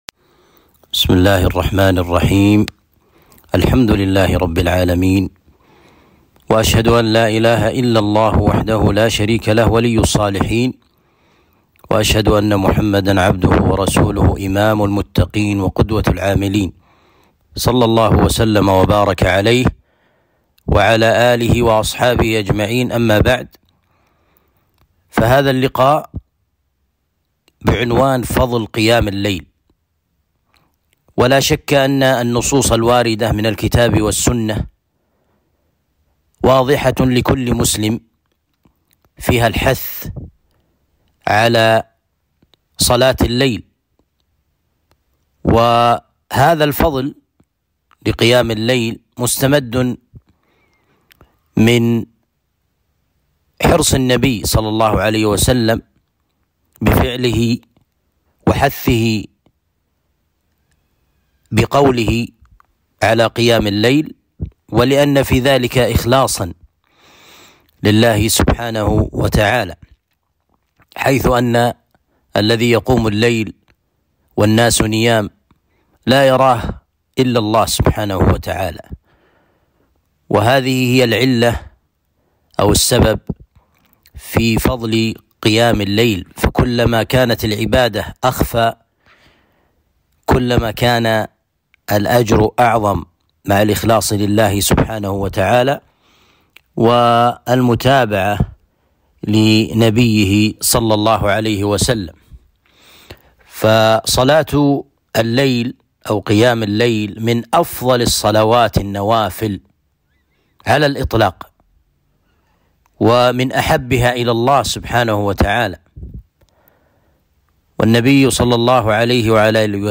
محاضرة بعنوان فضل قيام الليل